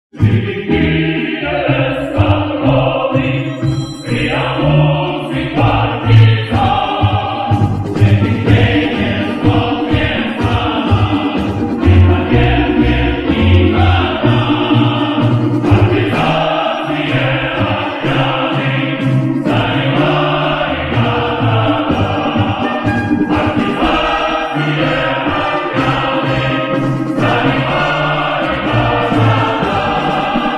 • Качество: 192, Stereo
мотивирующие
хор
военные
цикличные